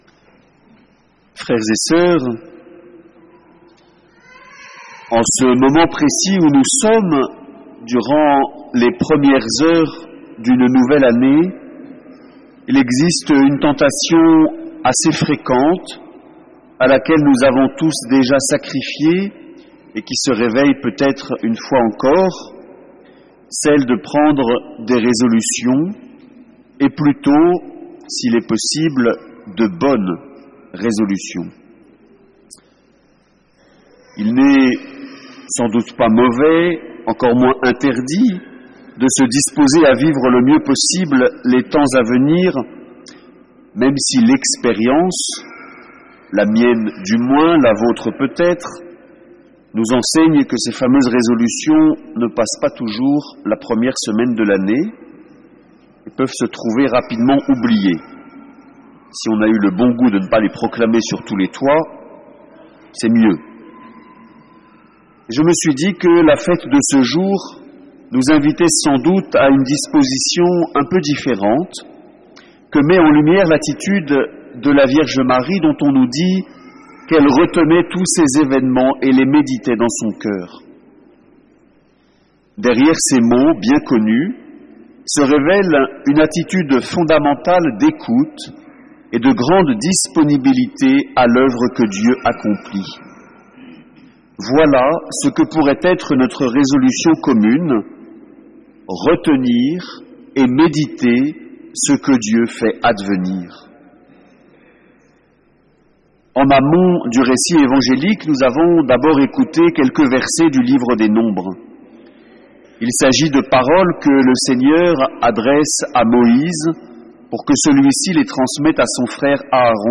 Catégories homélies